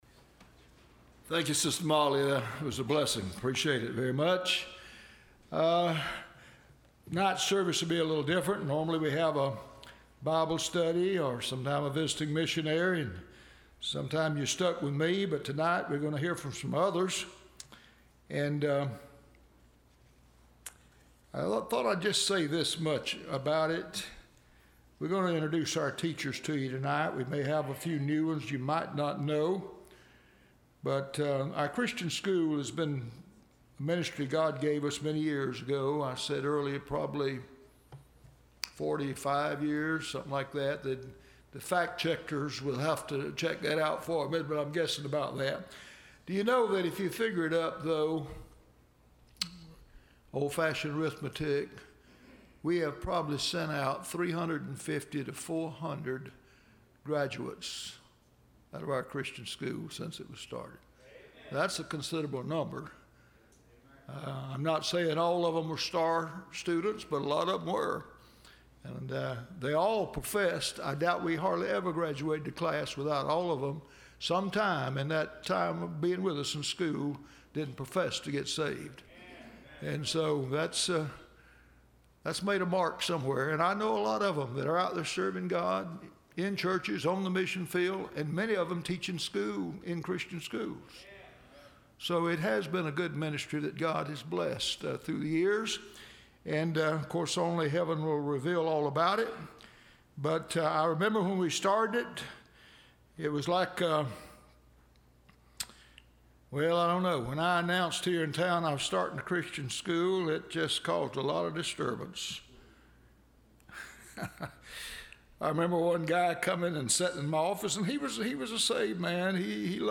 Teacher Testimonies – Landmark Baptist Church